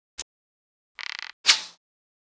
attack_act_1.wav